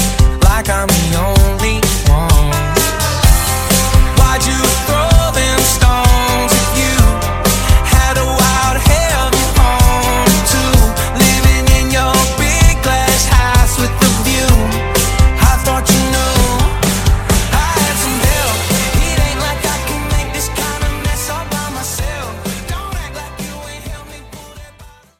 Dj Intro Outro
Genres: EDM , RE-DRUM , TOP40
Clean BPM: 130 Time